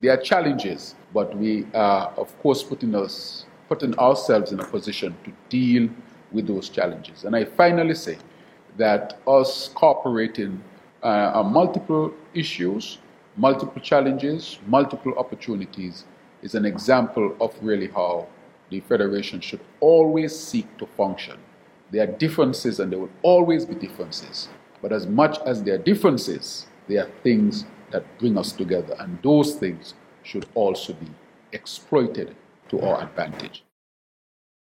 The Federal Cabinet of the Government of Saint Kitts and Nevis and the Nevis Island Administration (NIA) Cabinet held their first joint meeting for 2025, on Monday Mar. 17th, at Park Hyatt, St. Kitts.
Prime Minister, the Hon. Dr. Terrance Drew, mentioned that collaboration between the two Cabinets is essential: